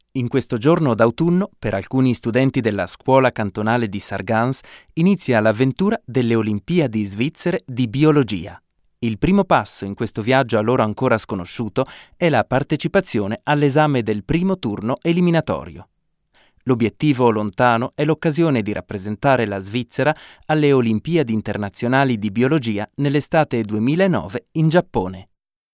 Sprecher Italienischer Muttersprache in der Schweiz.
Sprechprobe: Industrie (Muttersprache):